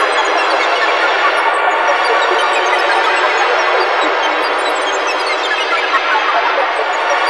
SI2 TEXTURE.wav